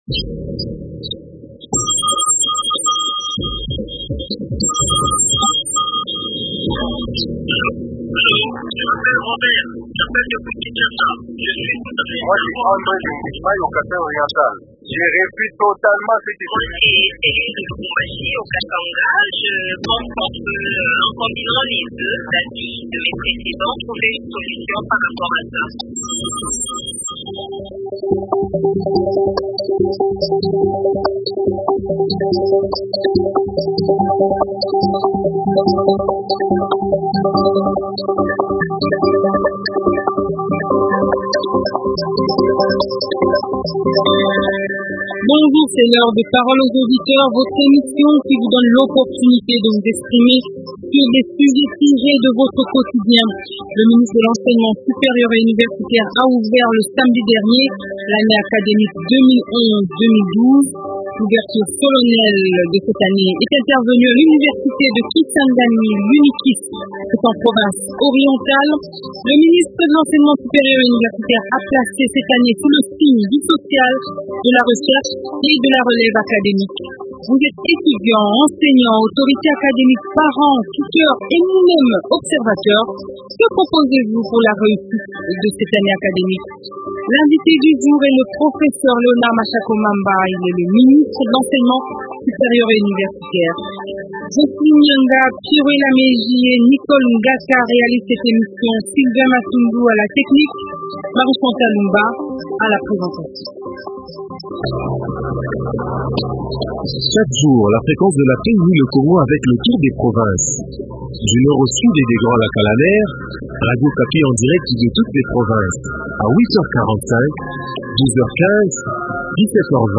Que proposez-vous pour réussir cette année ? Invité: Professeur Léonard Mashako Mamba, ministre de l’Enseignement supérieur et universitaire.